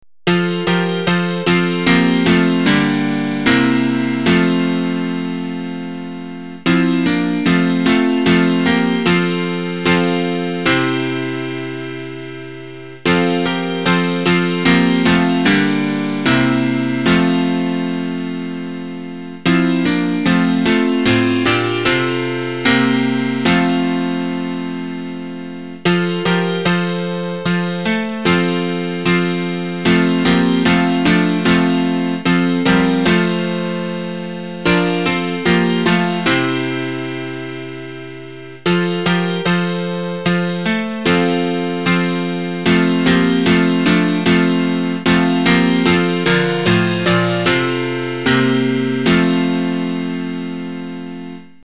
Midi file is modified to piano only.
Words: E. M. Wadsworth, 1910.